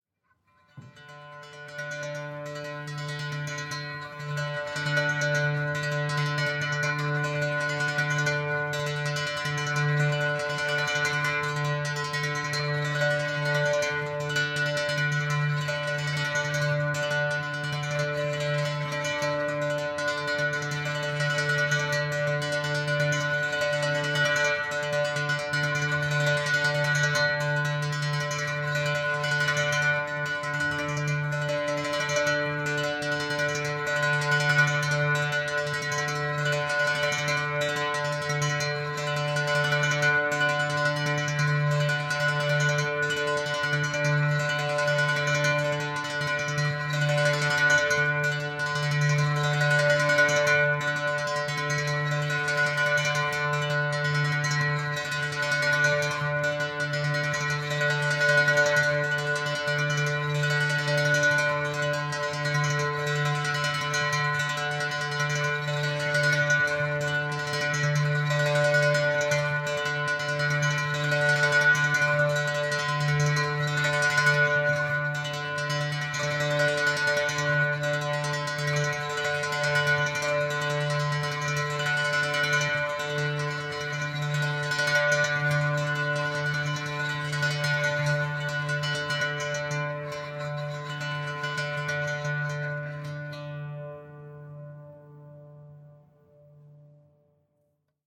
Unison Monochord therapy D - D - 129.mp3
Original creative-commons licensed sounds for DJ's and music producers, recorded with high quality studio microphones.
unison_monochord_therapy_d_-_d_-_129_g1n.ogg